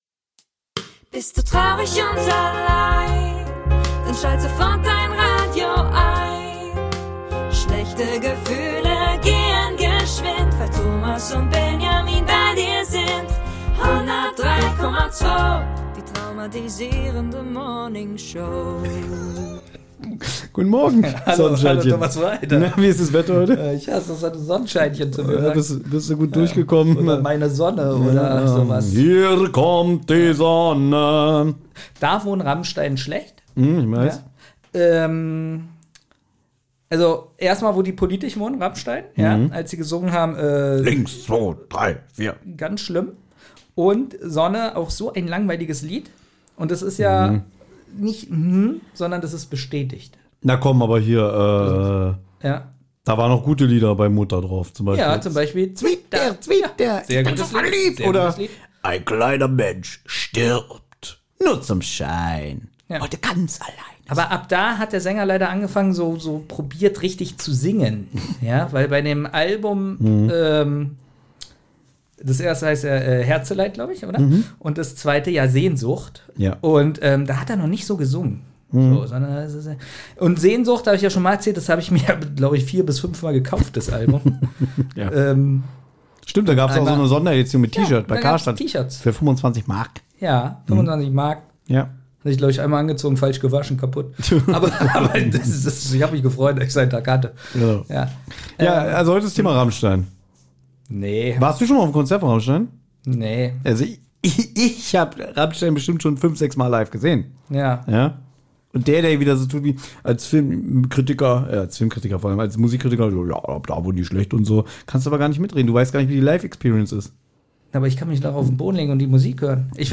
Im ewigen Duell, wer von den beiden der Schlauere ist, bekommen die beiden heute erneut Fragen über die Schweiz gestellt! Und das auch noch von einer waschechten Schweizerin!